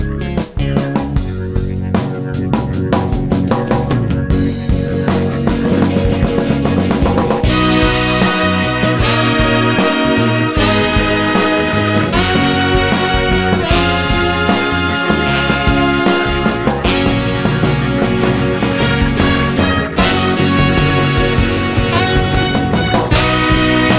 Live Version Pop (1960s) 3:45 Buy £1.50